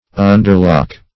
\un"der*lock`\